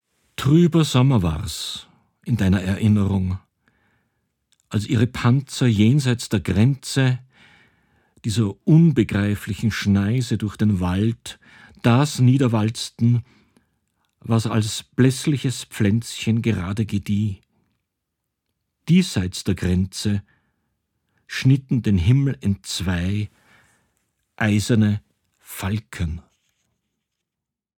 Musik von GrenzWertig